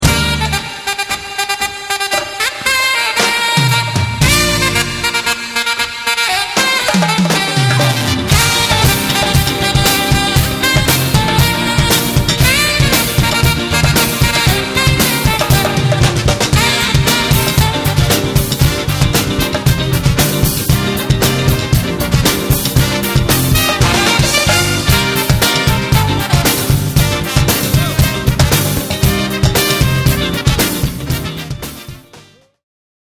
Some recordings may be clearer than others.
Jewish Hora Disco